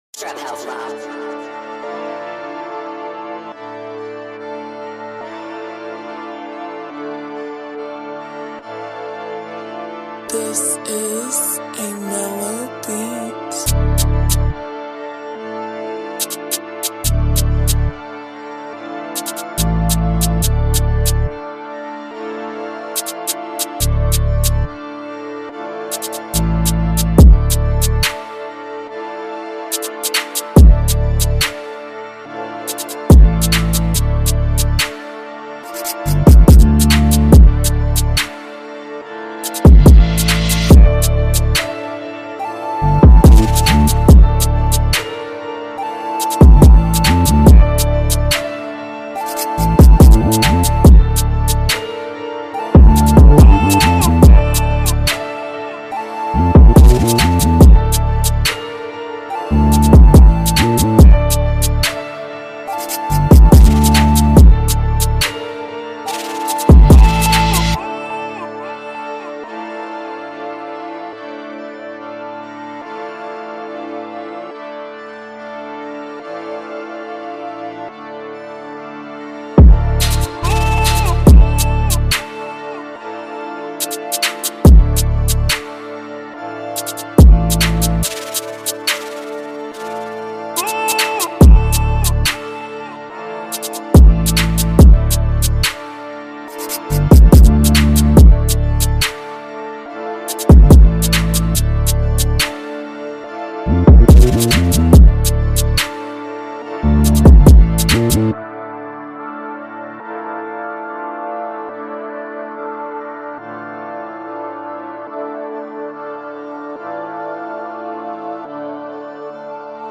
official instrumental
2020 in NY Drill Instrumentals